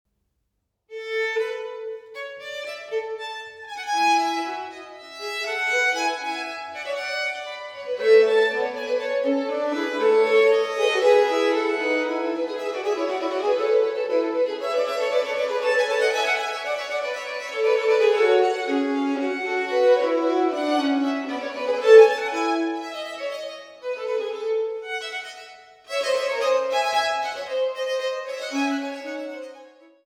Instrumetalmusik für Hof, Kirche, Oper und Kammer